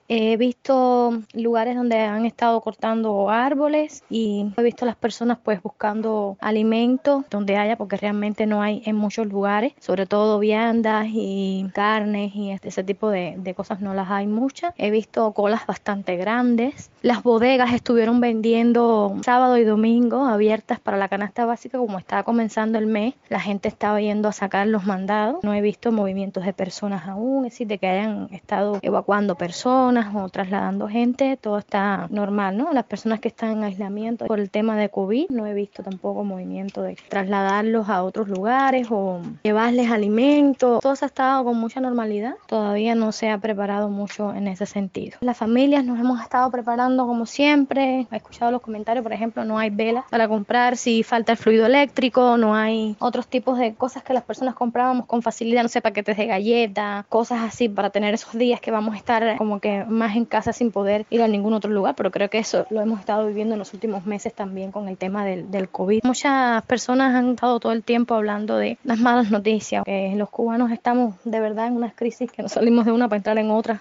Declaraciones desde Sancti Spritus